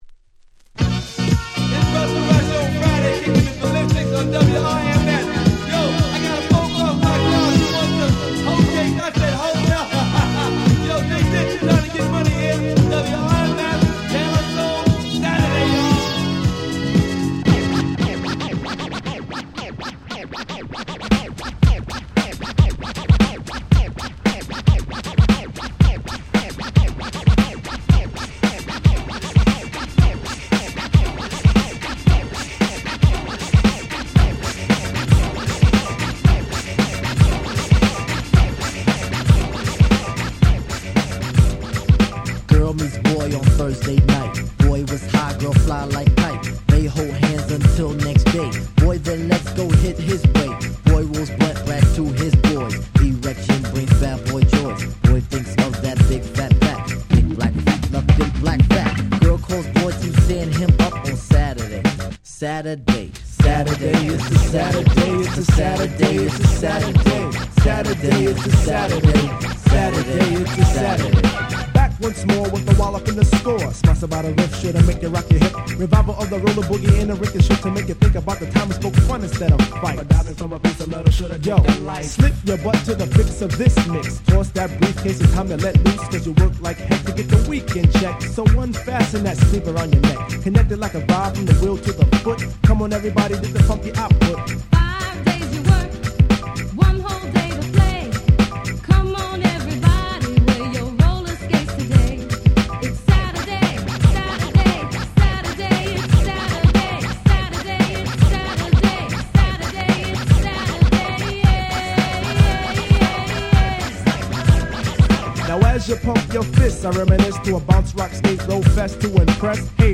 91' Smash Hit Hip Hop !!
Boom Bap